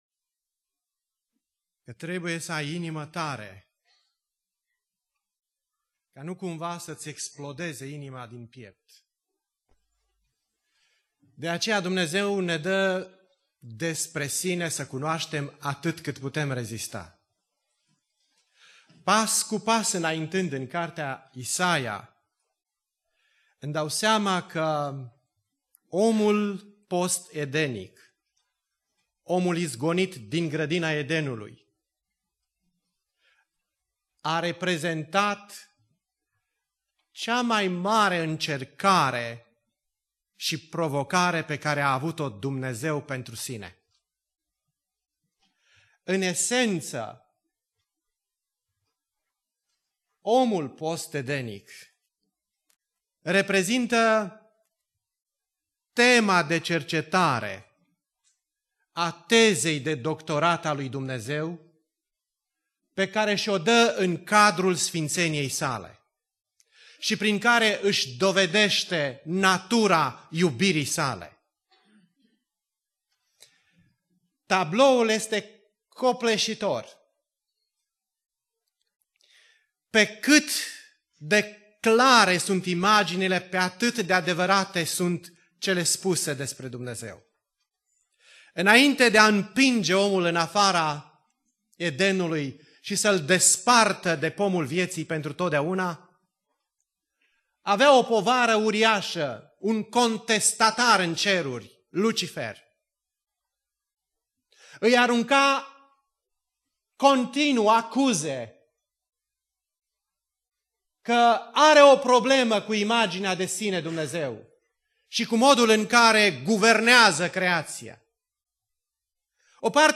Predica Aplicatie - Isaia 40-42